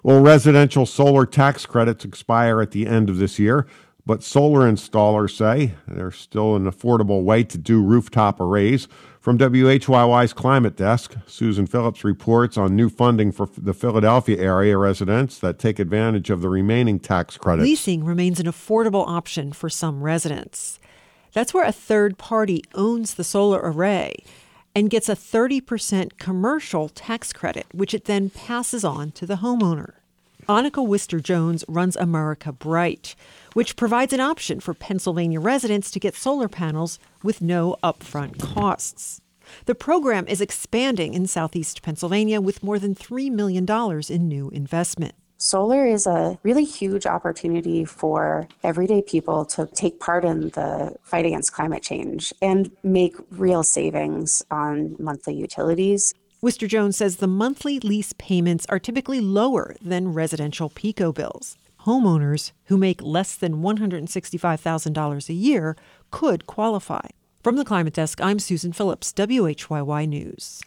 Clarence Clemons, saxophonist for Bruce Springsteen’s E Street Band, has had a stroke. Read the full story below from the Associated Press and enjoy the video above of Clemons performing the National Anthem at the Florida Marlins’ 2011 opening day game against the Mets.